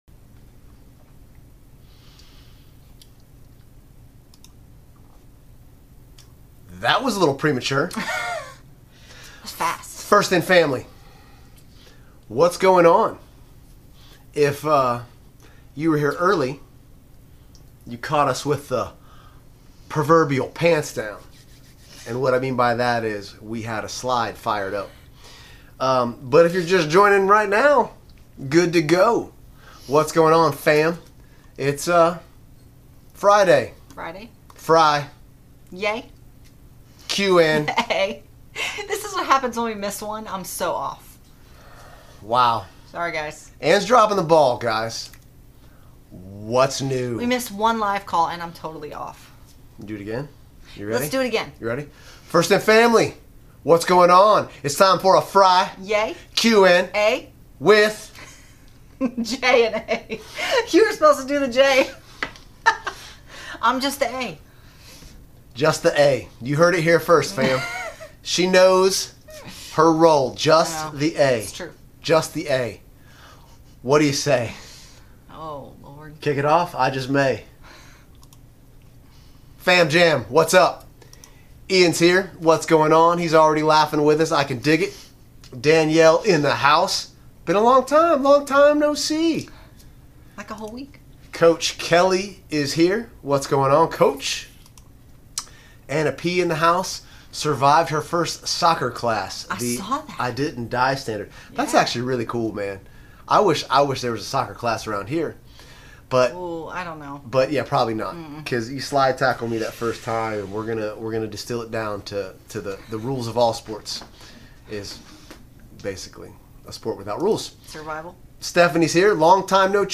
FIN LIVE Q+A: 4/14/23